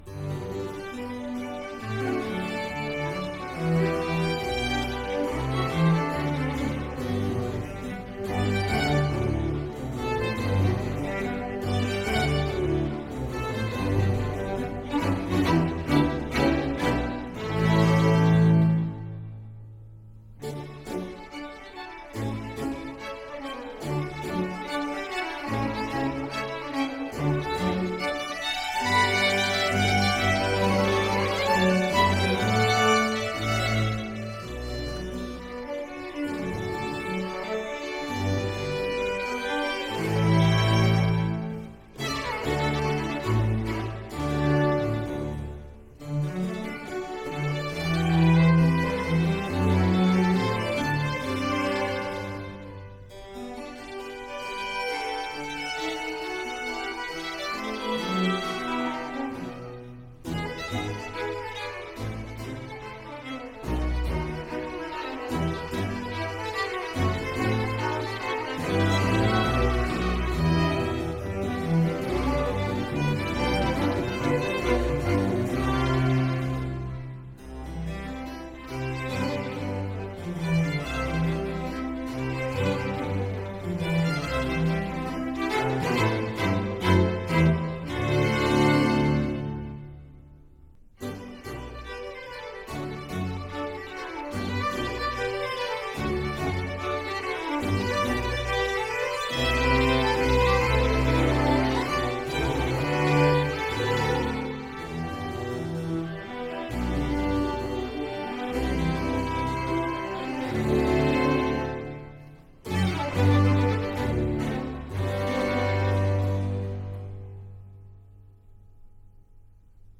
three violins, viola and two cellos